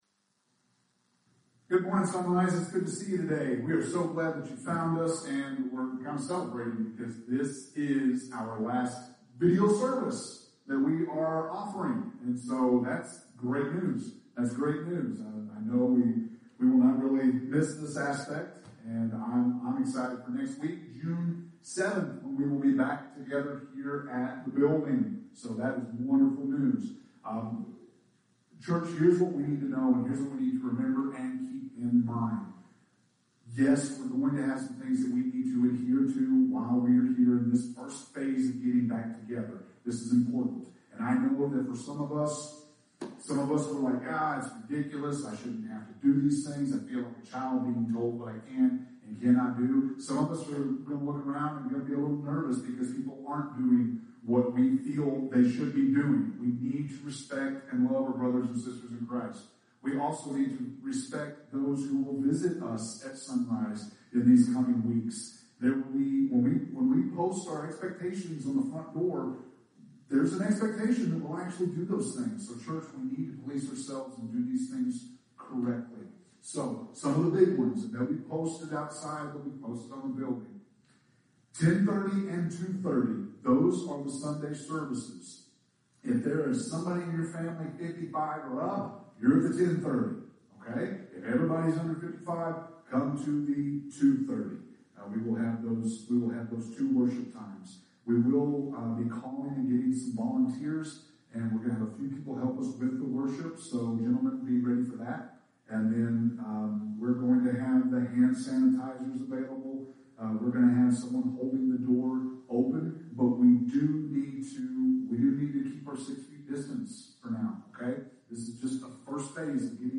May 31st – Sermons